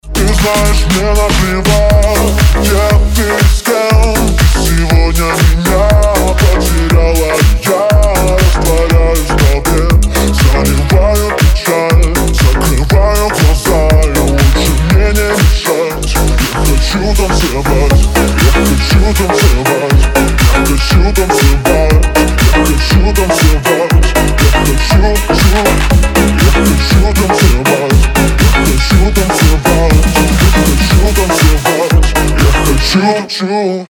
• Качество: 320, Stereo
dance
Club House
house